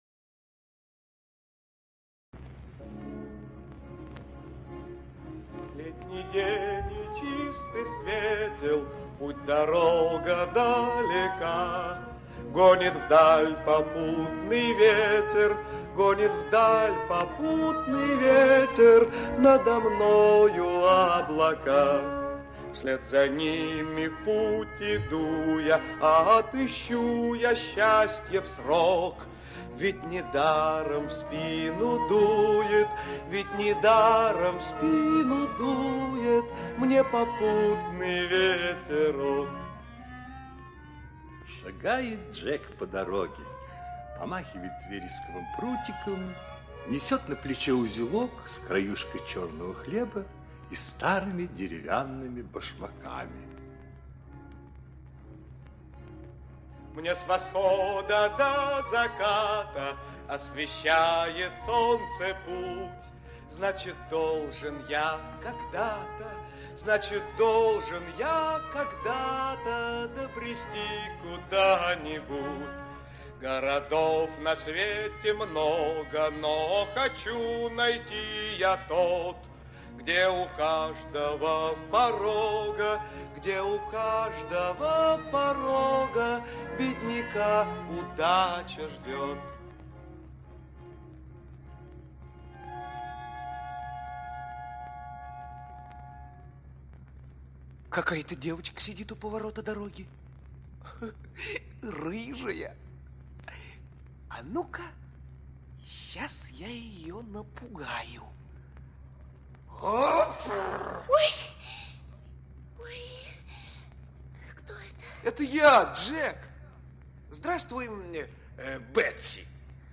Волшебные башмаки - английская аудиосказка. Сказка про веселого и доброго паренька Джека, у которого были волшебные башмаки.